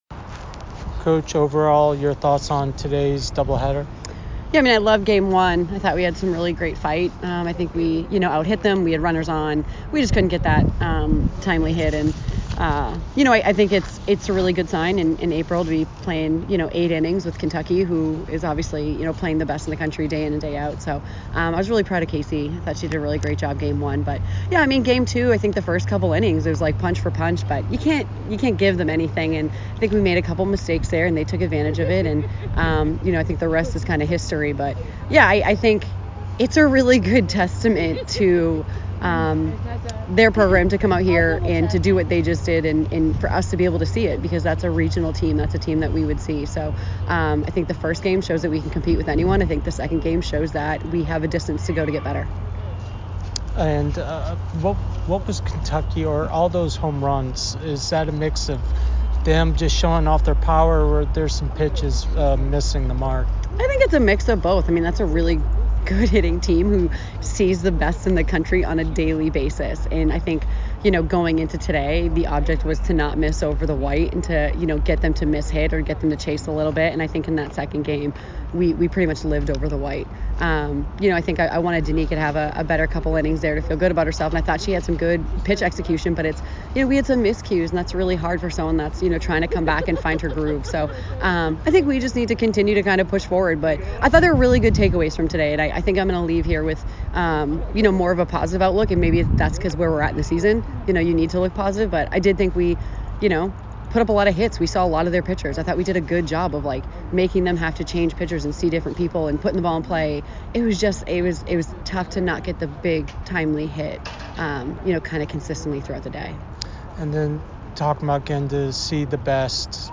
Kentucky DH Postgame Interview